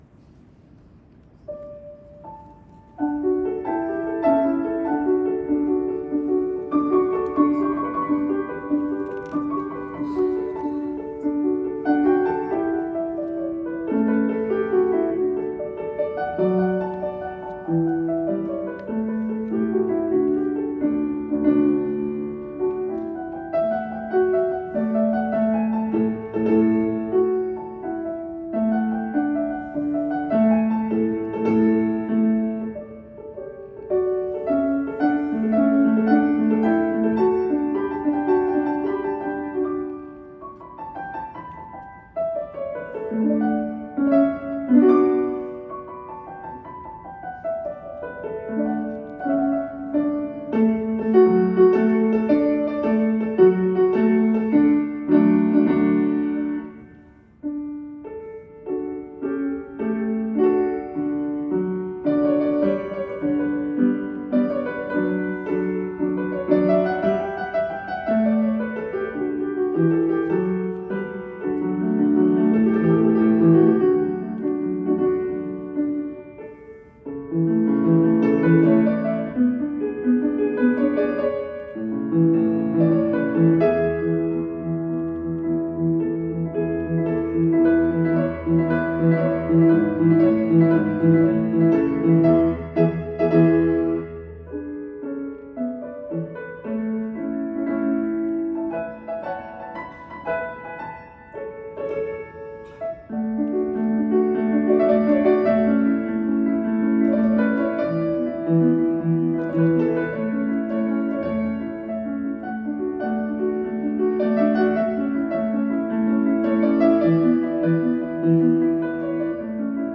Another piece adapted from the opera is Domenico Corri’s piano arrangement of the celebrated coloratura soprano aria “The Soldier Tir’d”; it is included in a printed album of Corri’s works for solo piano that belonged to Austen and has only recently been rediscovered.4
in Cleveland, Ohio